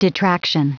Prononciation du mot detraction en anglais (fichier audio)
Prononciation du mot : detraction